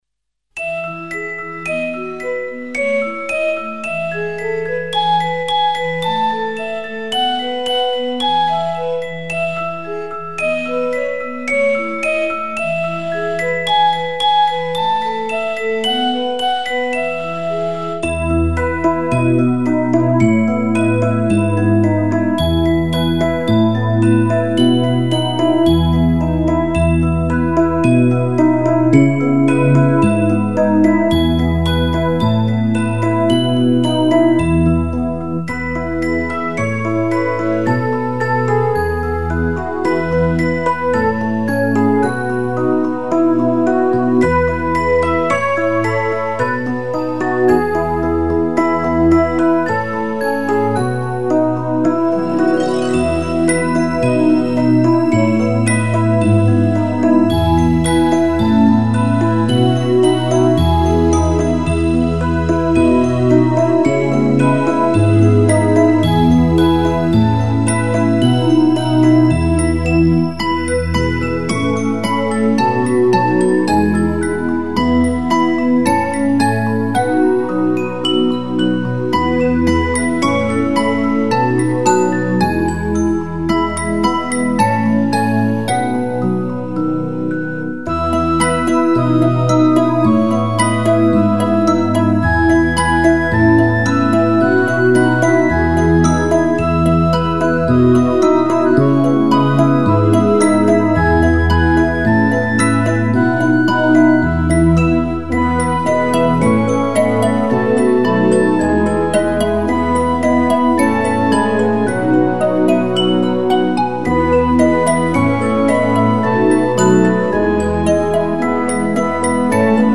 键盘
精致、细腻，满足宝宝娇嫩敏感的小耳朵。